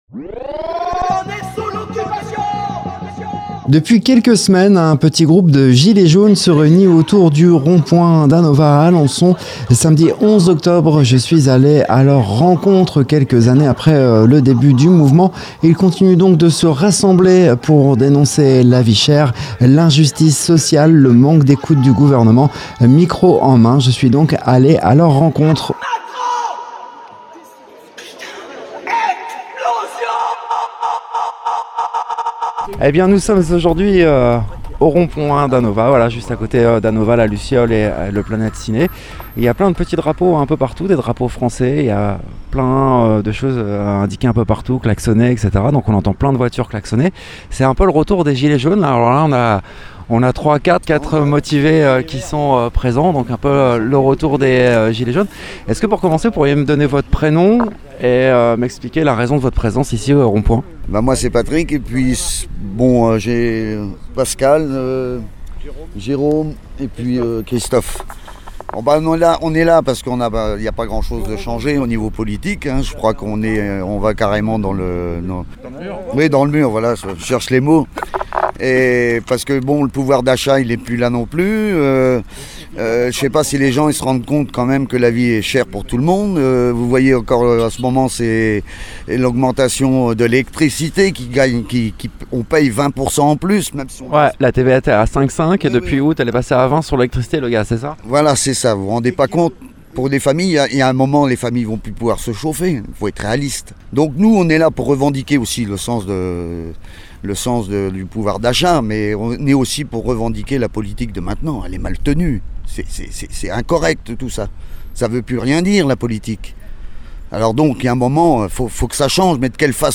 Samedi 11 octobre 2025, nous avons passé l’après-midi avec les Gilets jaunes d’Alençon au rond-point d’Anova. Entre banderoles, échanges spontanés et circulation qui ne s’arrête jamais, ce reportage donne la parole à celles et ceux qui se mobilisent localement.
Qu’est-ce qui a changé — ou pas — depuis les débuts du mouvement ? Place aux témoignages, à l’ambiance et aux sons du terrain.
Reportage chez les Gilets Jaunes du 13.10.2025